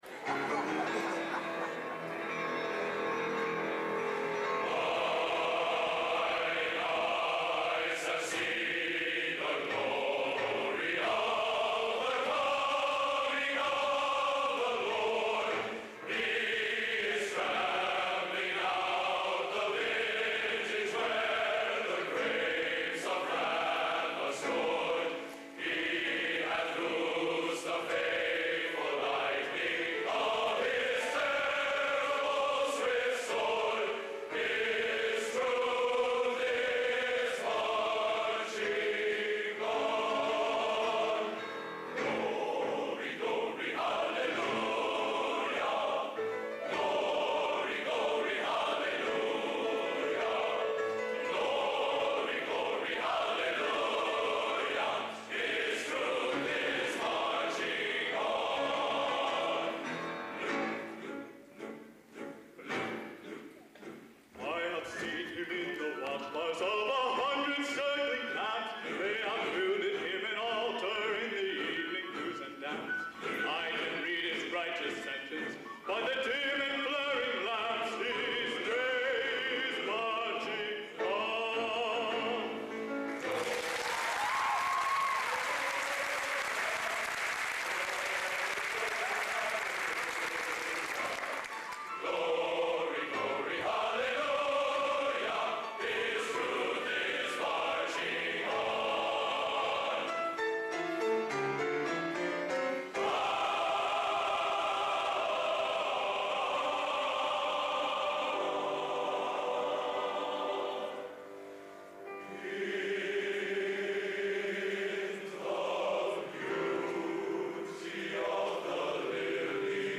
Location: Purdue Memorial Union, West Lafayette, Indiana
Genre: Patriotic | Type: